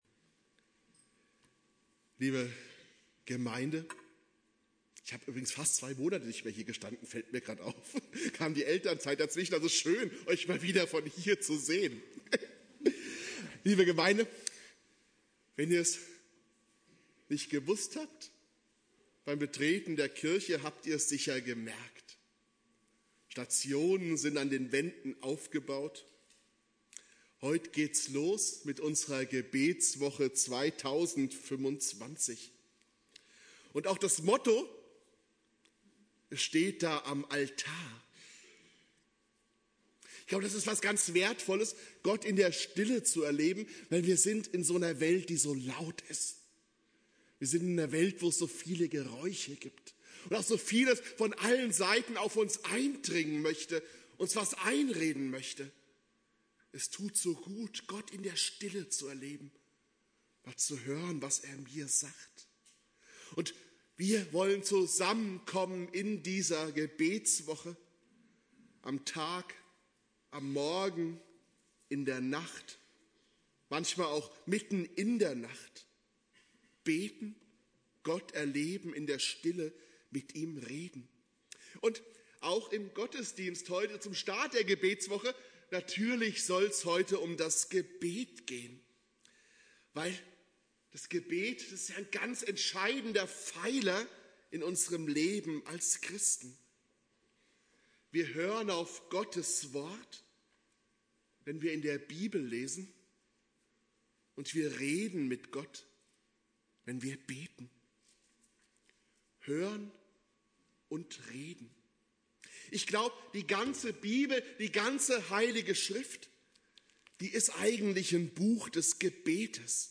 Thema: Biblische Gebetsperle - Das Gebet des Jabez Inhalt der Predigt